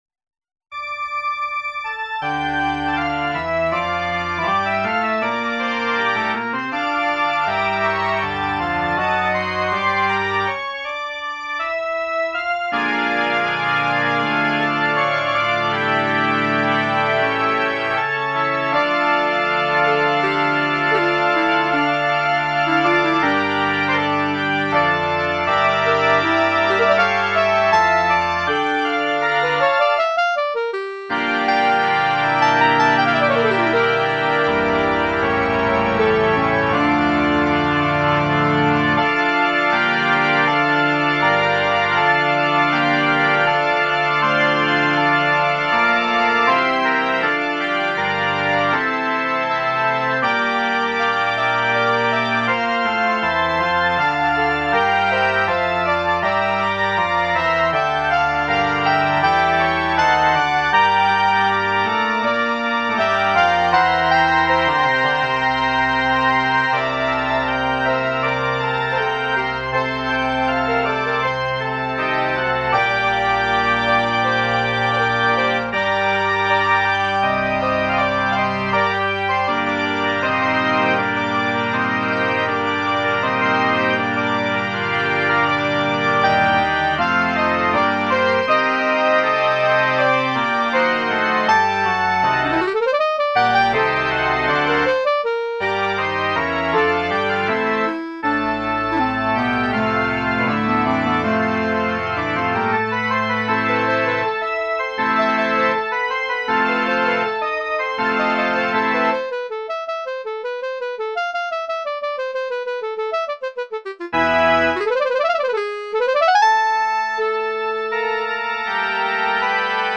Besetzung: Instrumentalnoten für Klarinette